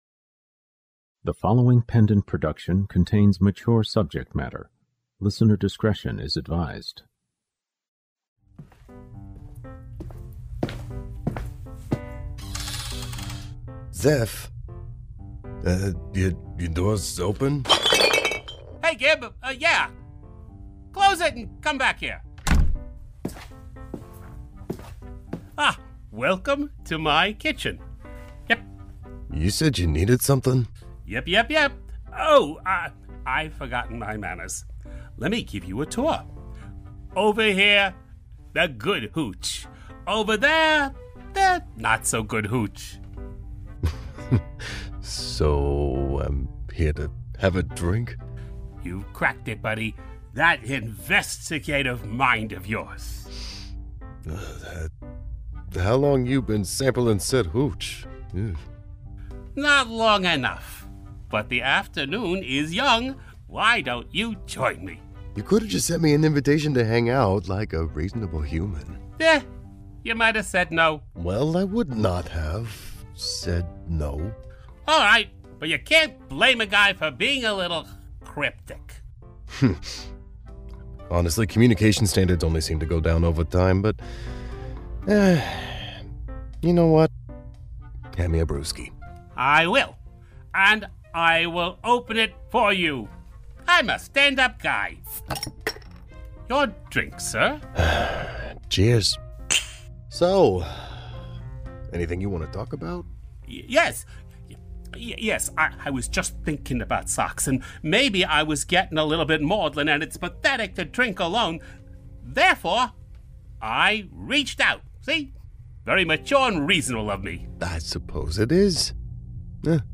The Kingery sci-fi crime audio drama.